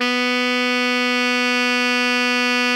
Index of /90_sSampleCDs/Giga Samples Collection/Sax/ALTO 3-WAY
ALTO  FF B 2.wav